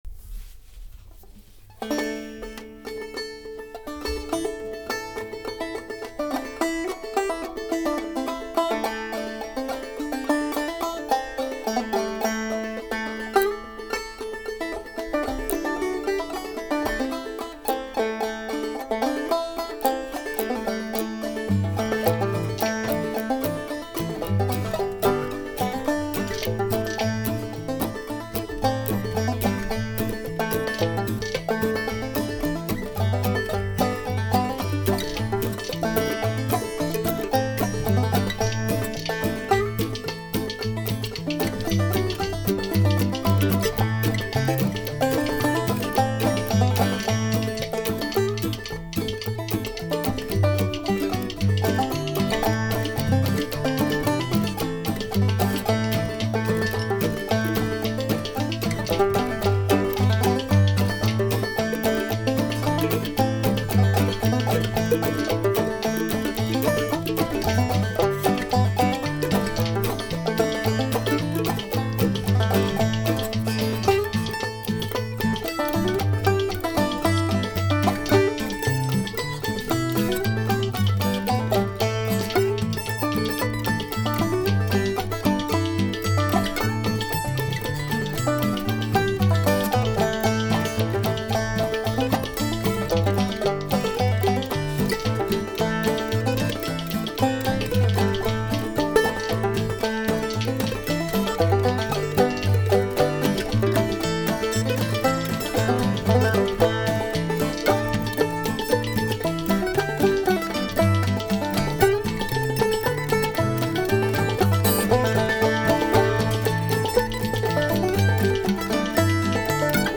Frosty Morning, traditional, banjo, guitar, mandolin and bones